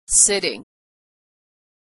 Voiceless /t/
When /t/ is stressed, it has a puff of air.
When /t/ is not stressed, it does not have a puff of air.